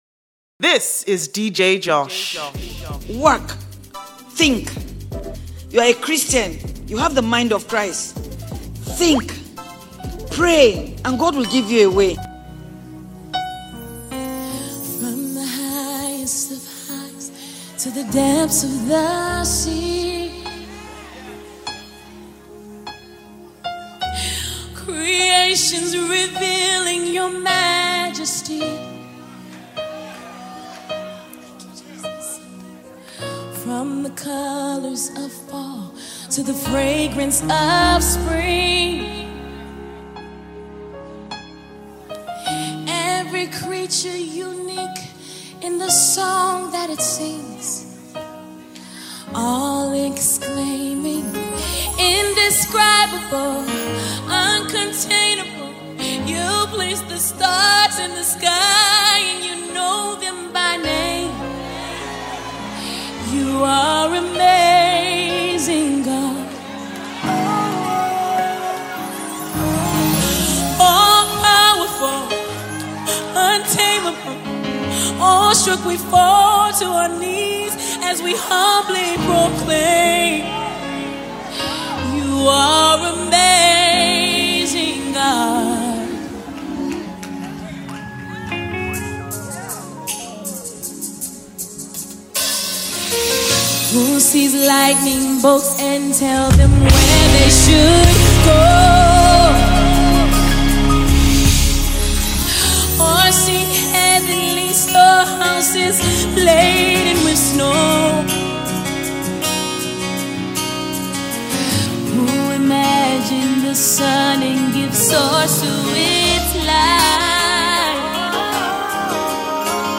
worship mix